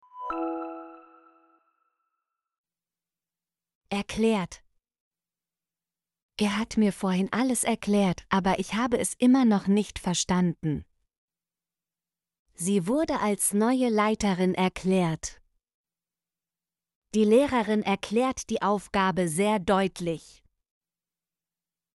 erklärt - Example Sentences & Pronunciation, German Frequency List